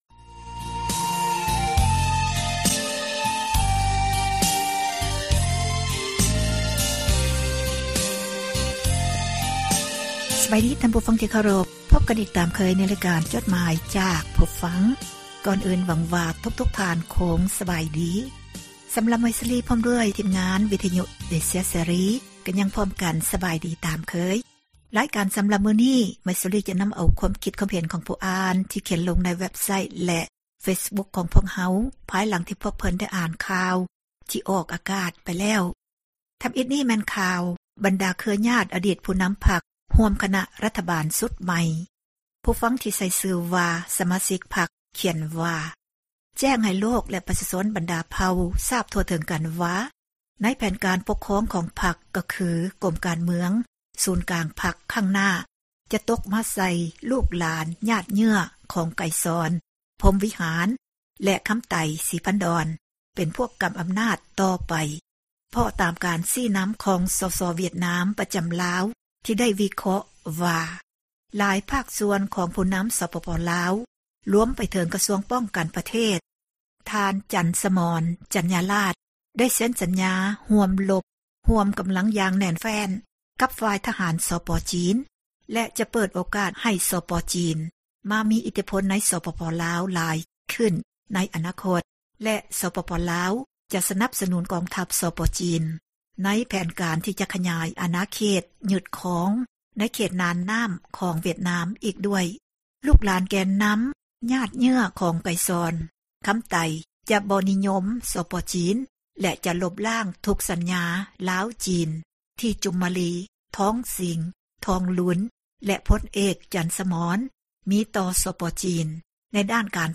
ອ່ານຈົດໝາຍ